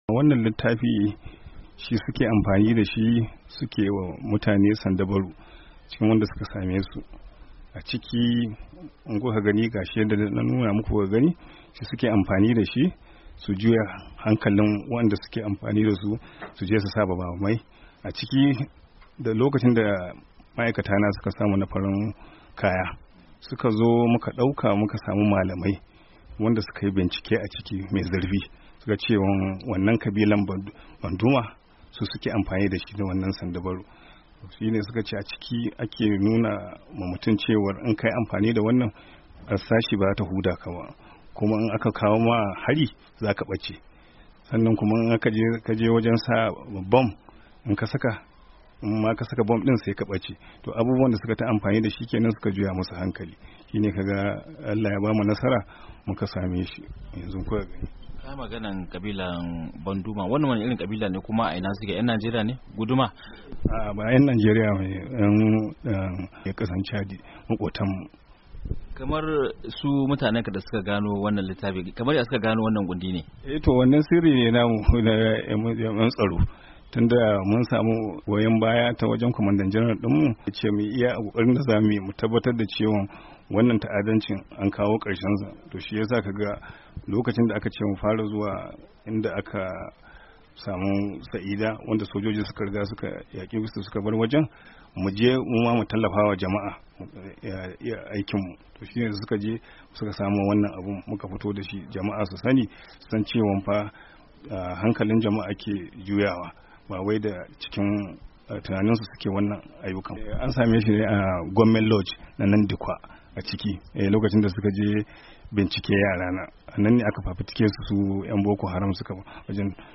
Domin jin cikakkiyar hirar